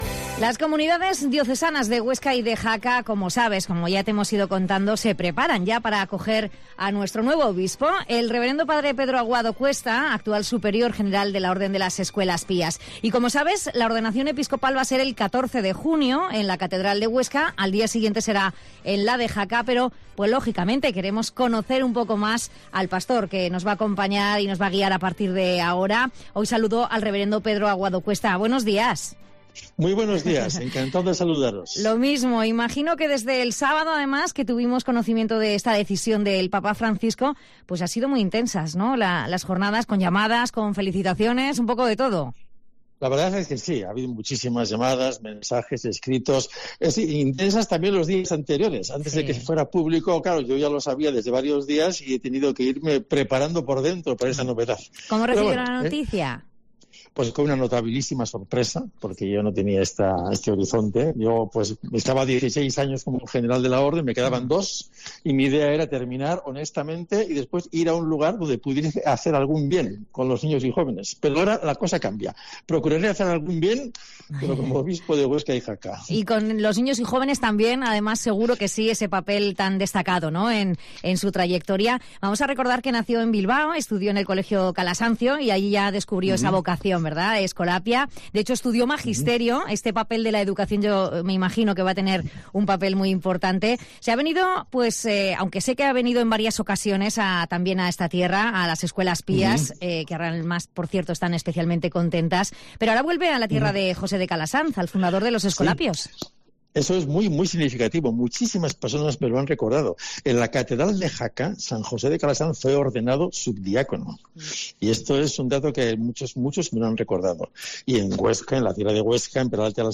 El padre Pedro Aguado será el nuevo obispo de Huesca y de Jaca y en declaraciones a COPE Huesca ha asegurado que quiere «llegar, escuchar y compartir».